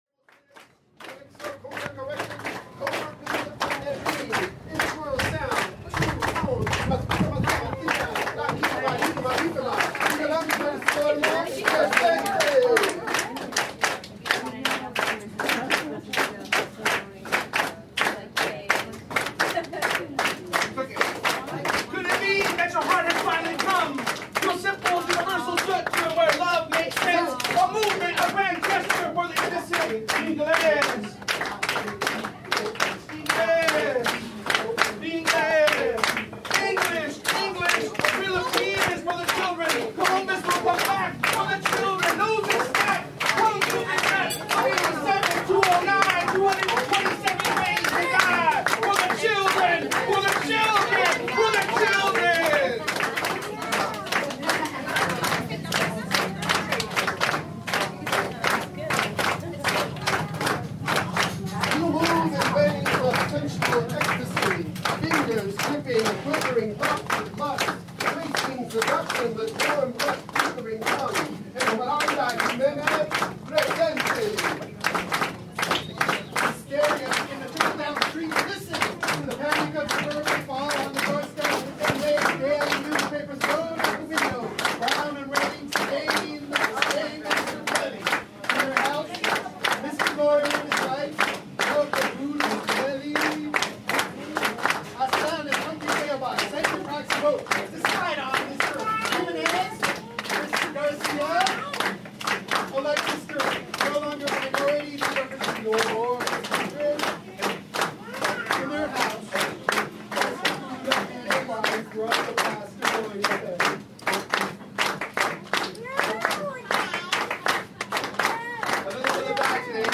Avenue 50 Studio’s “Poesia Para Le Gente” was presented through “The Taco Shop Poets” at Taqueria My Taco on 6300 York Boulevard on May 26th, 2012.
Here is a short sample of the energy and poets in the room.
Partial Event recording.